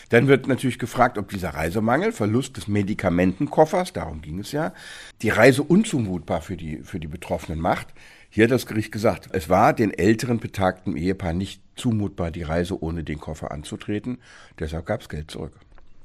O-Ton: Medikamente aus Bus gestohlen – Senioren bekommen Kreuzfahrt-Kosten zurück – Vorabs Medienproduktion